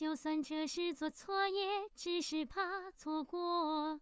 singing